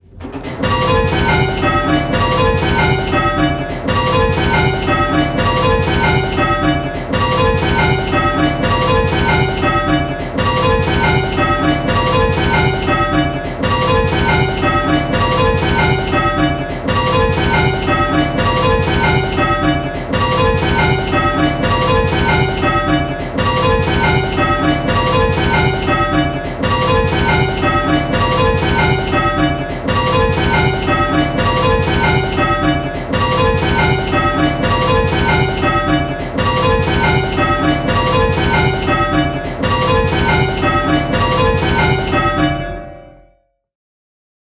Hearing 6 bells...
We know that hearing specific bells can be difficult, so in these clips we have kept things very simple… in each clip 1 bell is ringing early… and consistently so, at both hand and backstroke.
Click the play button and listen to the rhythm of the bells…. the rhythm will sound a bit lumpy!
Rounds-4-early.m4a